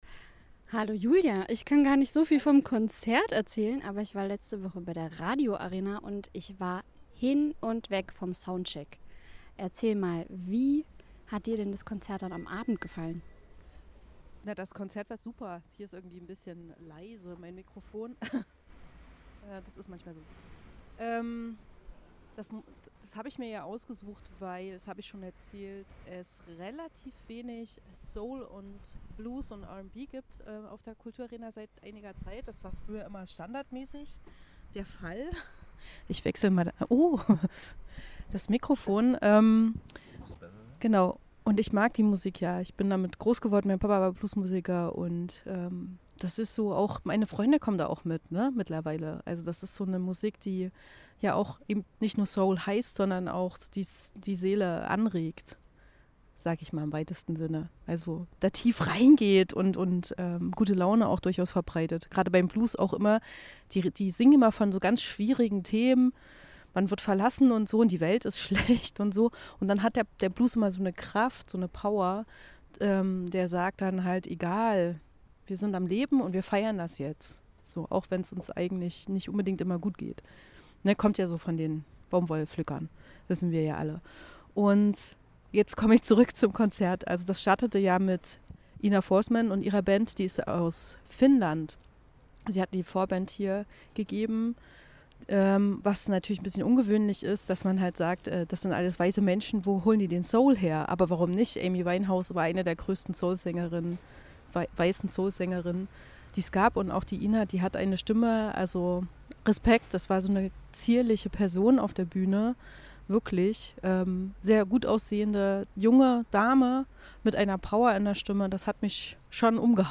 Liveberichterstattung vom Theatervorplatz.
RadioArena Konzertrezension Ina Forsman & Robert Finley